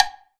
9HIWOODBL.wav